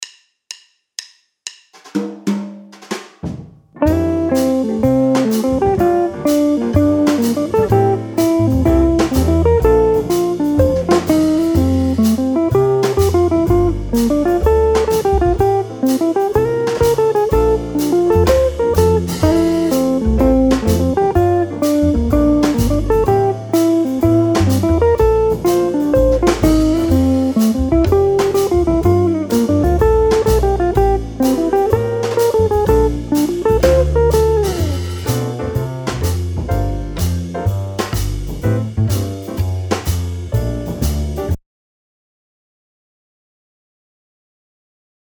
1 Jazz backing track (mp3)